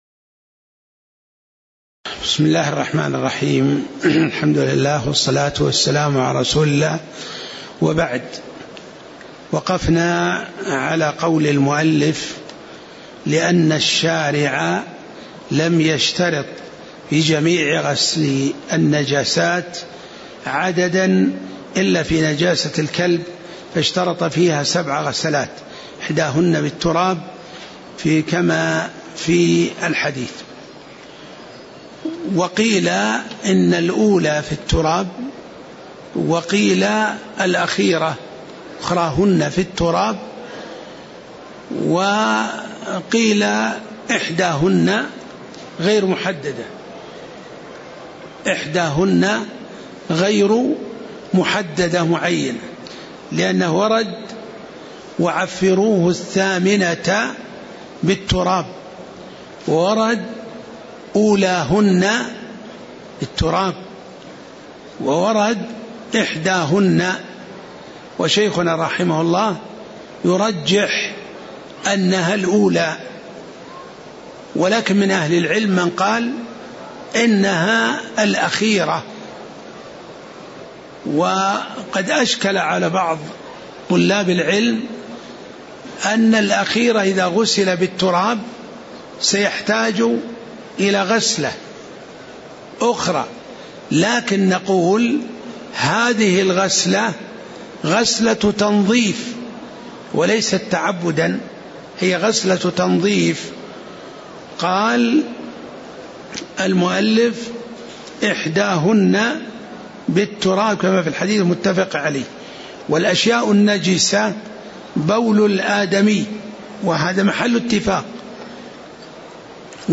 تاريخ النشر ١٢ شوال ١٤٣٧ هـ المكان: المسجد النبوي الشيخ